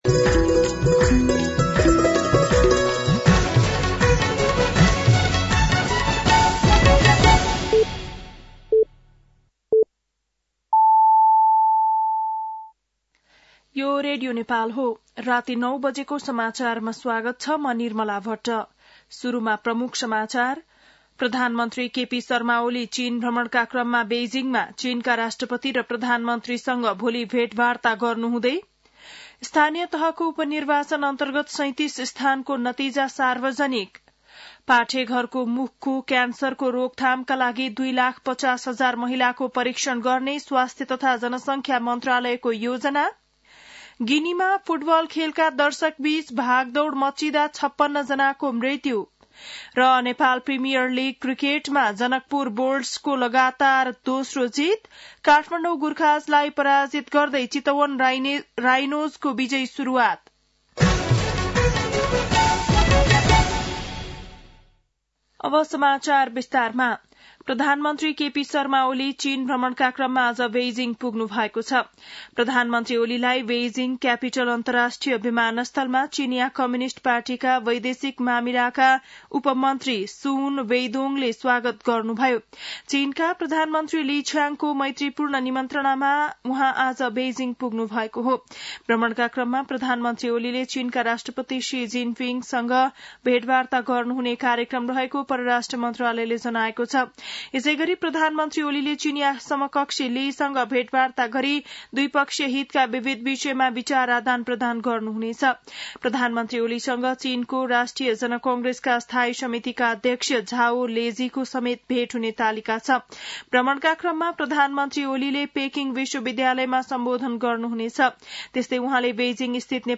बेलुकी ९ बजेको नेपाली समाचार : १८ मंसिर , २०८१